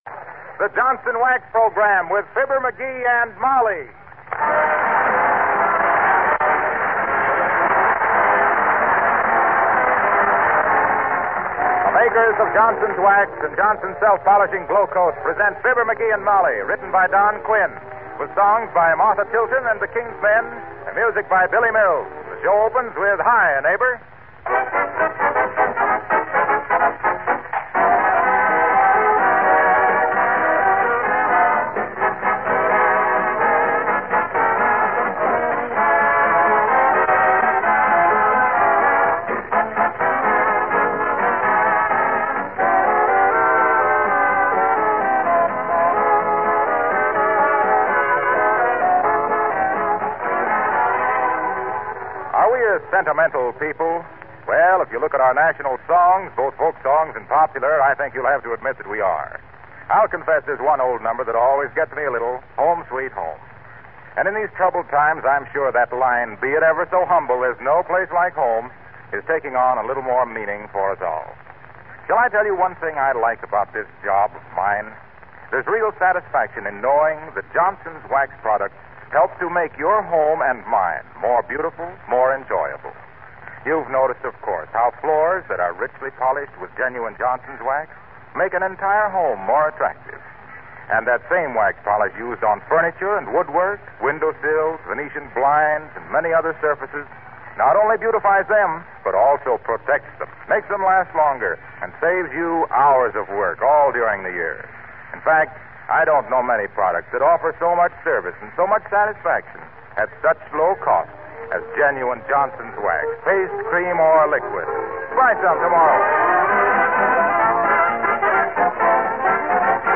Fibber McGee and Molly was an American radio comedy series.
The title characters were created and portrayed by Jim and Marian Jordan, a real-life husband and wife team that had been working in radio since